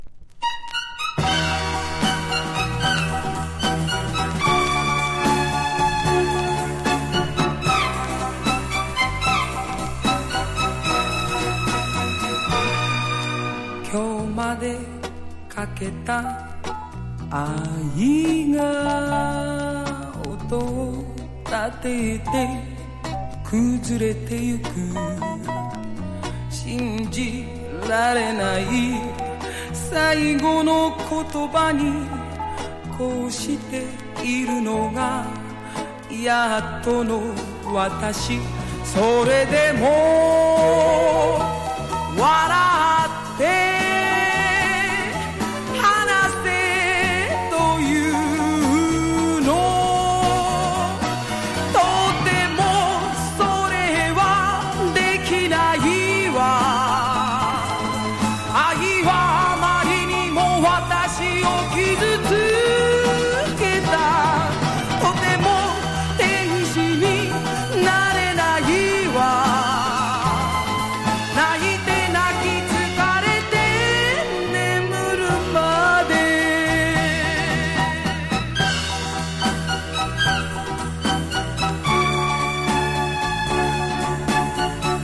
形式 : 7inch / 型番 : / 原産国 : JPN
日本が誇るソウル・シンガーの71年の8th！
和モノ / ポピュラー